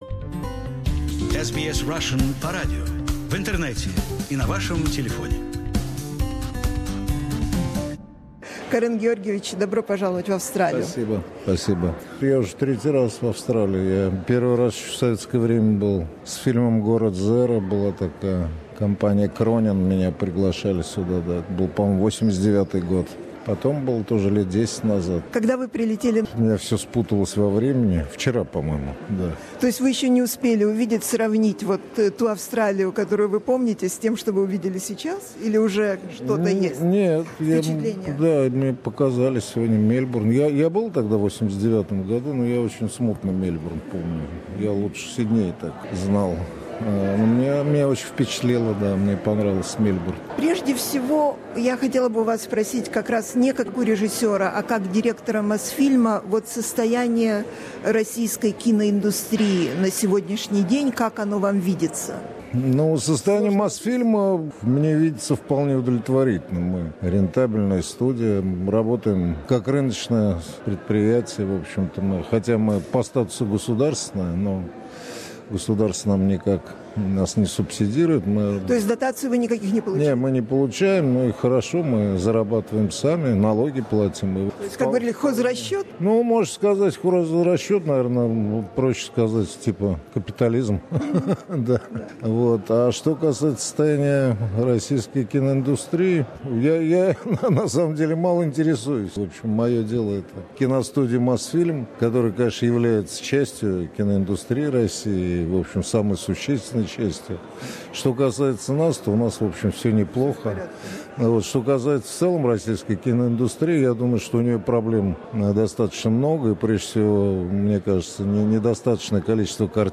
Мы беседуем с самым, пожалуй, именитым гостем кинофестиваля «Русское Возрождение» в Австралии в этом году – Кареном Шахназаровым, советским и российским кинорежиссёром, сценаристом, кинопродюсером и общественным деятелем.